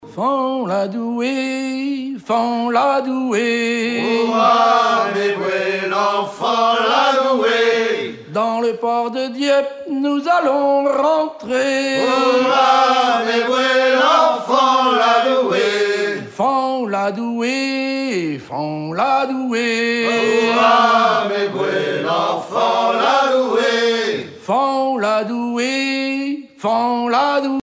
Région ou province Normandie
gestuel : à haler
circonstance : maritimes
Genre laisse